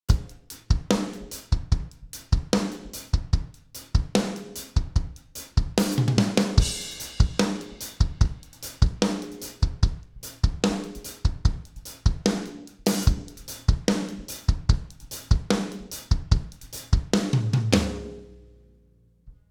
HPF 30 Hz
LF boost at 70 Hz
HF boost at 12 kHz
LMF Wide Boost (FOCUS OUT) at 700 Hz
HMF Wide Boost (FOCUS OUT) at 7 kHz
UV EQ Drums EQ.wav